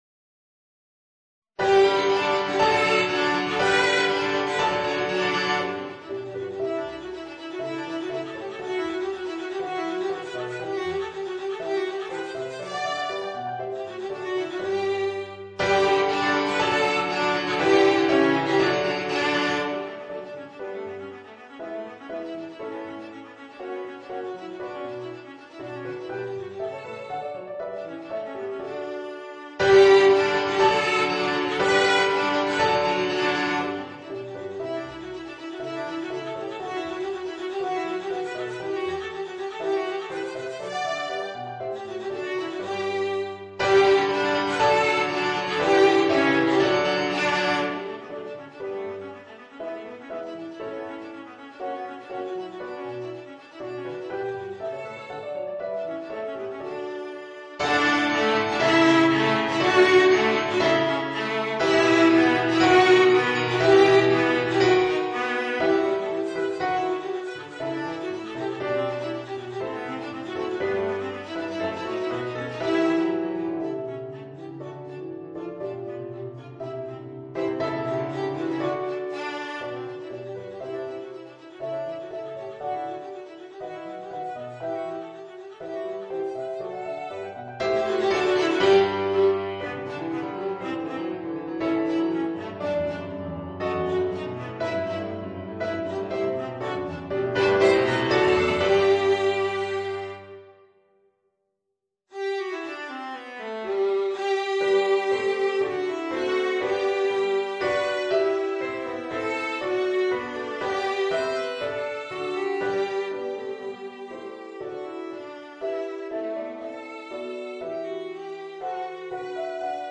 Voicing: Viola and Piano